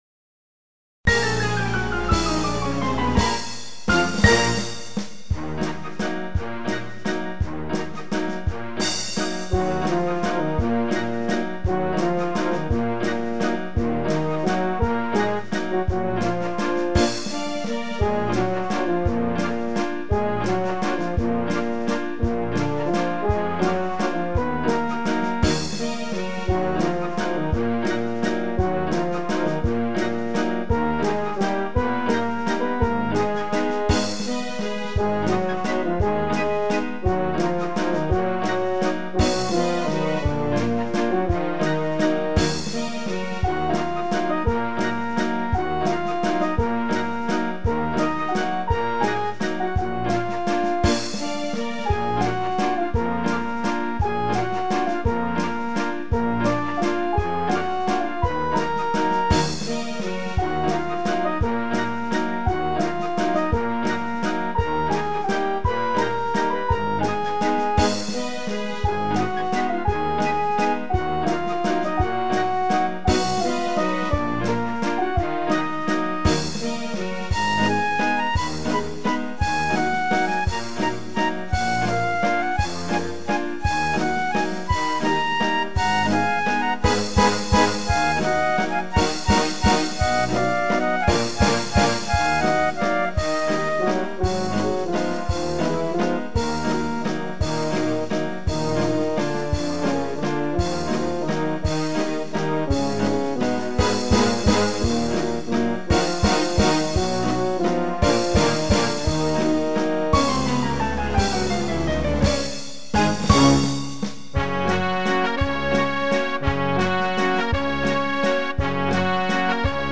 vals-duhovogo-orkestra-instrum..mp3